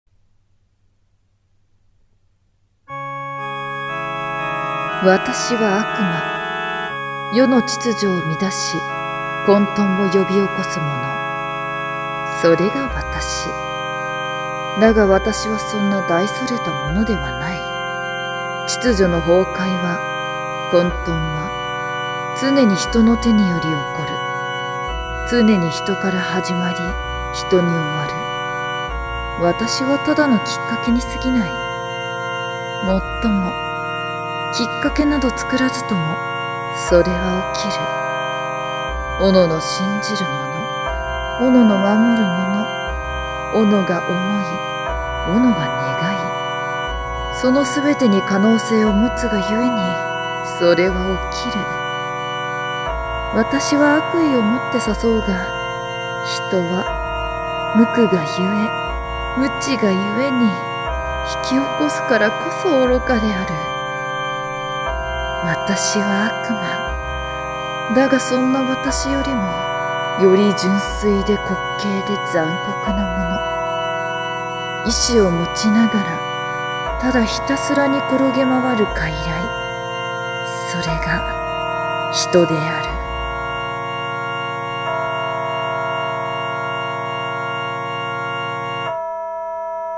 厨二声劇 『悪魔で人』